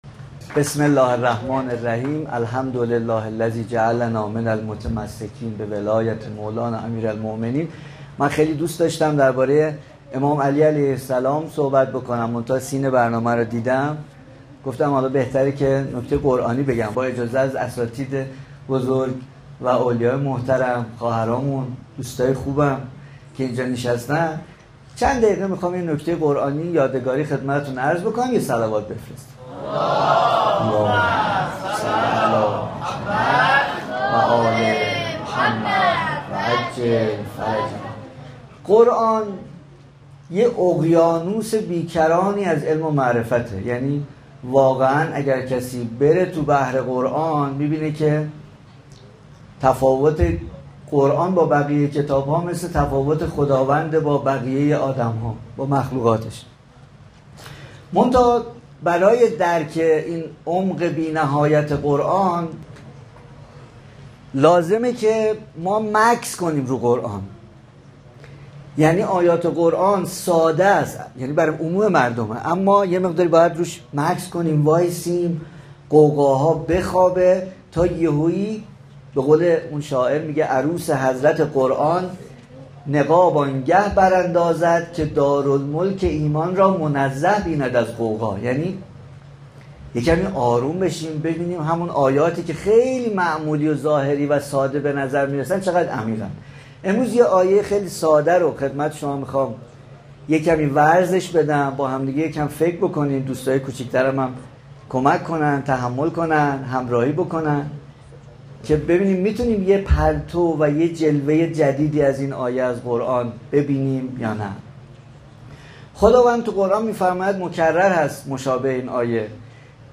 محفل انس با قرآن کریم با حضور قاریان کشوری و بین‌المللی در حسینیه فاطمه‌الزهرا(س) و مسئولان سازمان اوقاف و امور خیریه برگزار شد.